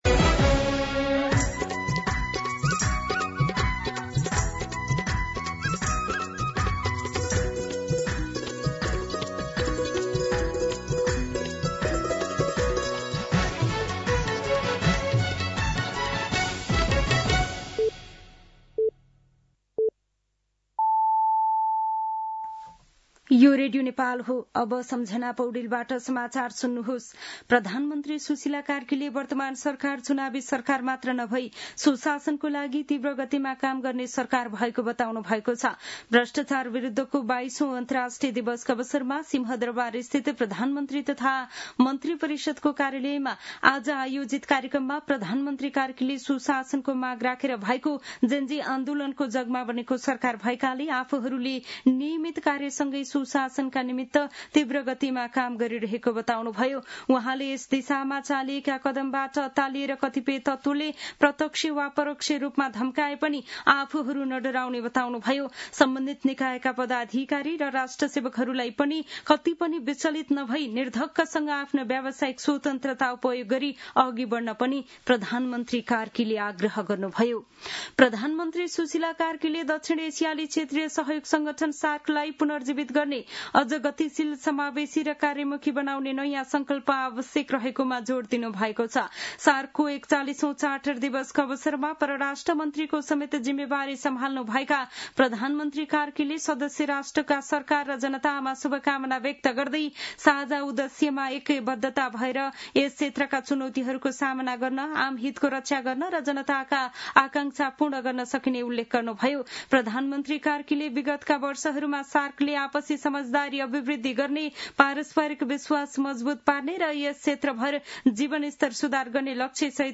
दिउँसो १ बजेको नेपाली समाचार : २३ मंसिर , २०८२